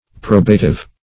Probative \Pro"ba*tive\, a. [L. probativus: cf. F. probatif.]